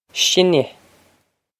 Sinne shin-ah
Pronunciation for how to say
This is an approximate phonetic pronunciation of the phrase.